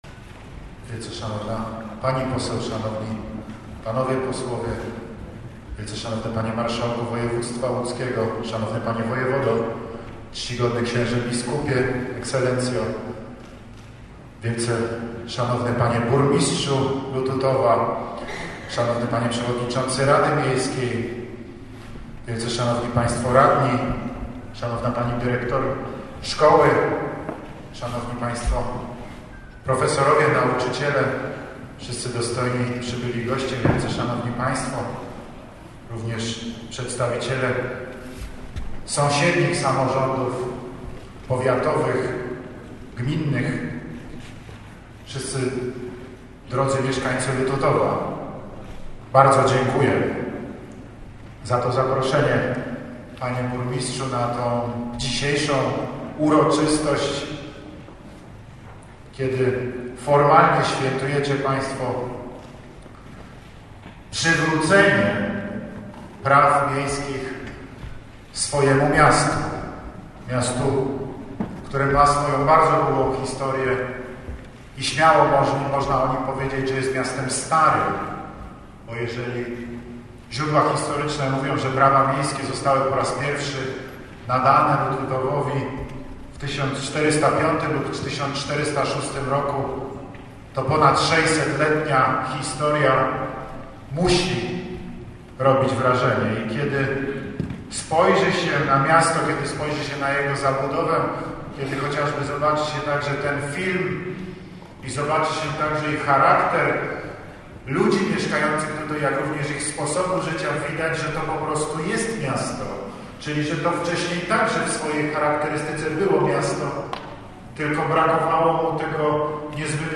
Wcześniej, po mszy świętej celebrowanej przez biskupa kaliskiego Edwarda Janiaka, na miejscowym rynku odsłonięto pomnik powstały dla uczczenia nadania praw miejskich dla Lututowa. Główna część uroczystości odbyła się w Szkole Podstawowej.
Miastu, które ma bardzo długą historię i śmiało można o nim powiedzieć, że jest miastem starym – mówił w swoim wystąpieniu Prezydent RP, Andrzej Duda.